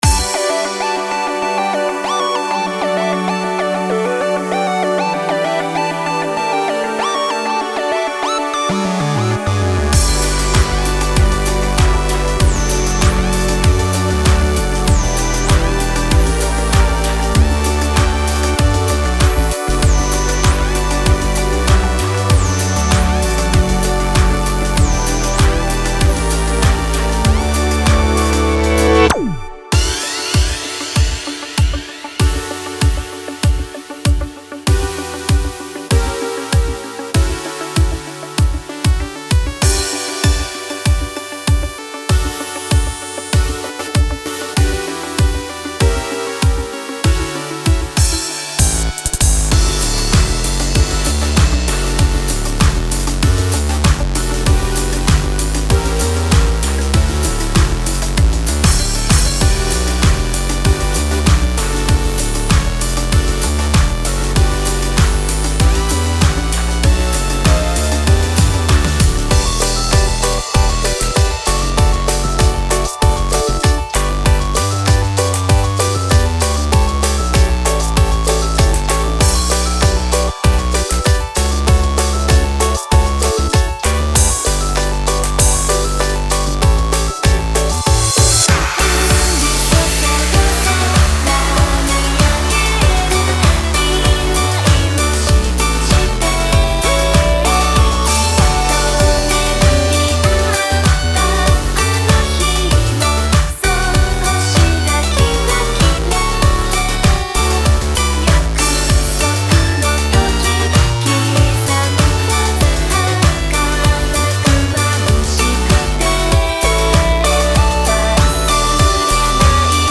下のガイドメロディなしバージョンでお楽しみください☆